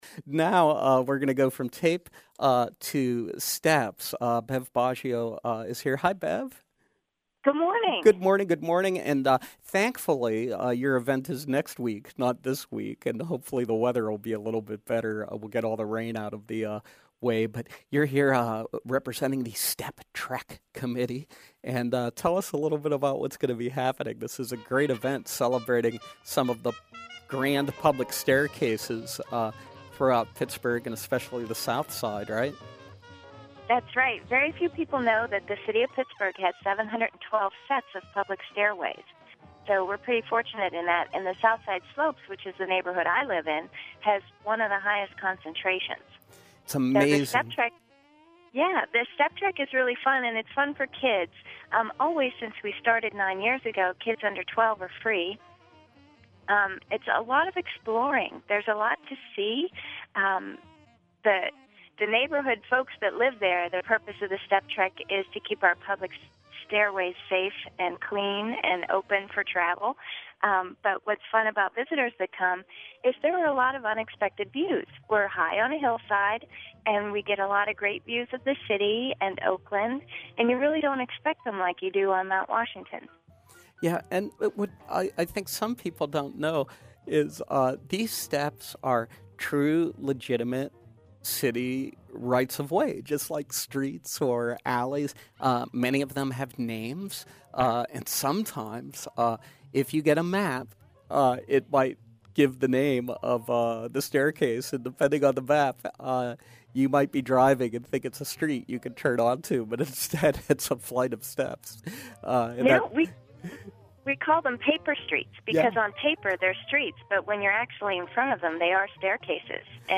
Step Trek interview